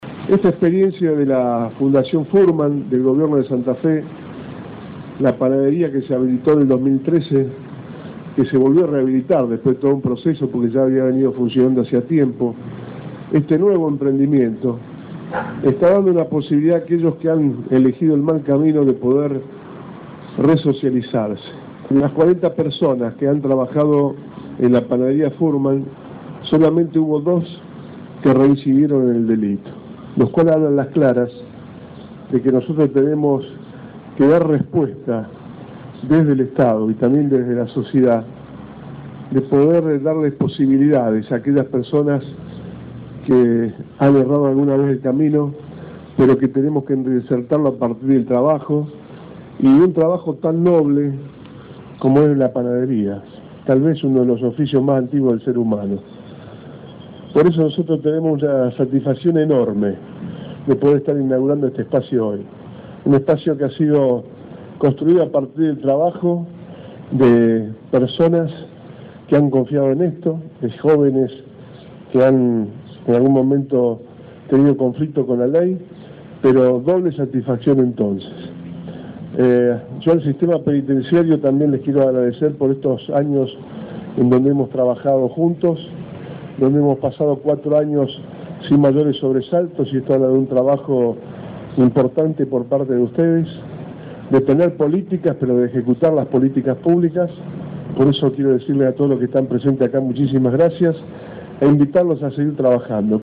“El Estado y la sociedad deben brindar posibilidades de reinserción a aquellas personas que han errado alguna vez el camino”, afirmó Bonfatti al inaugurar el salón de ventas “La Sarita del Norte” en Blas Parera al 8800.
Palabras de Antonio Bonfatti.